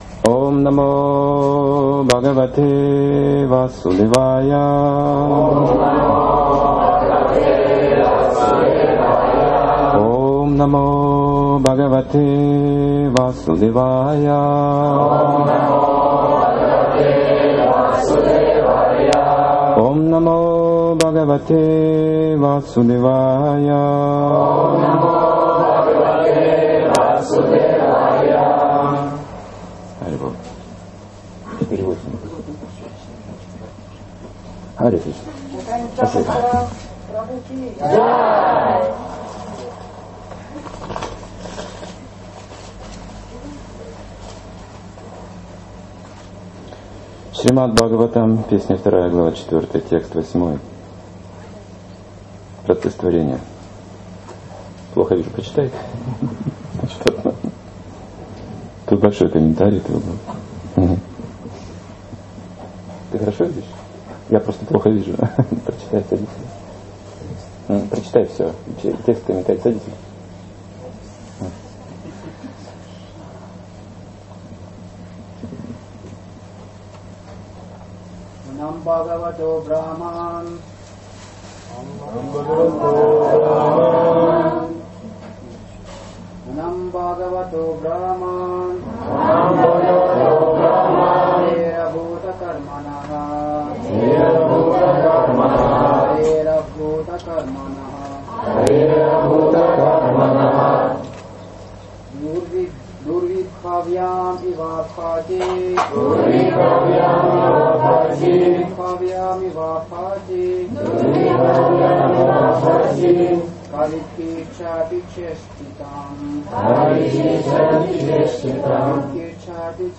Темы, затронутые в лекции: Непостижимость Господа Причина всех причин Честная жизнь Игры Господа Чистота Важность мотива Совершенство отречения История йогамая и камса Совершенное знание